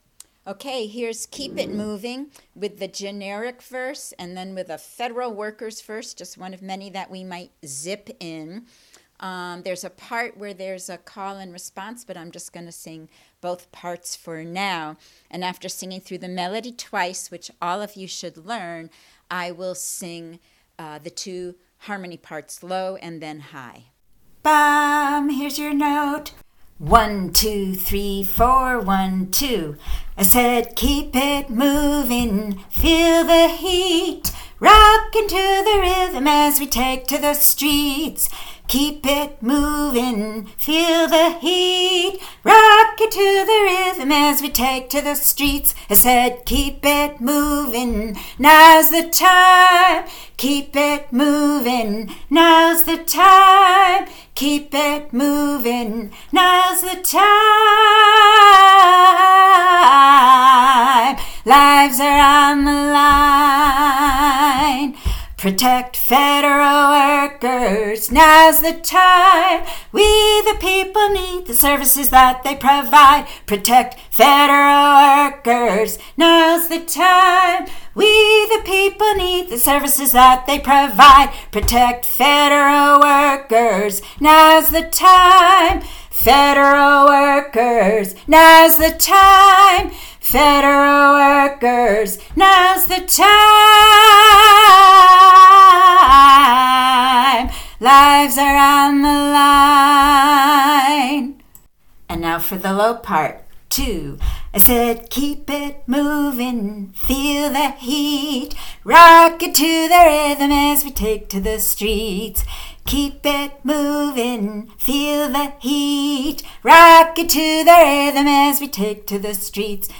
Keep It Movin mel-low-high.mp3